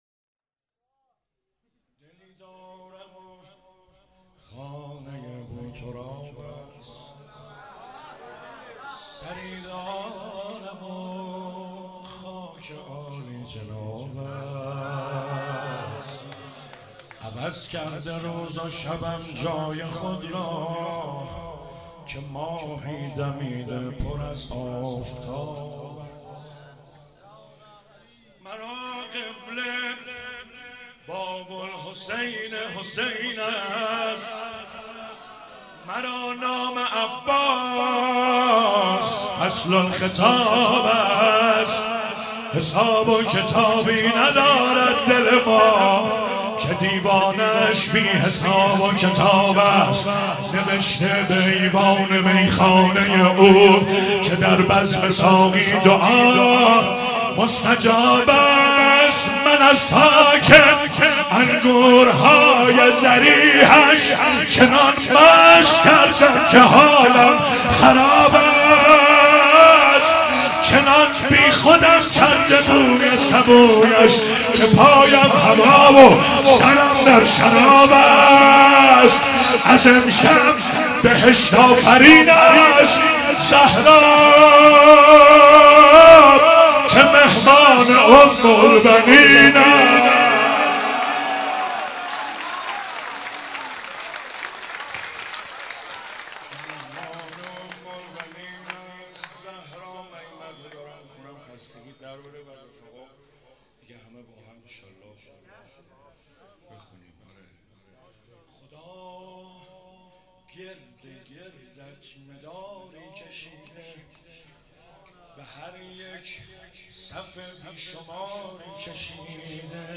ولادت امام حسین علیه السلام 97 - مدح - دلی دارم و خانه بوتراب است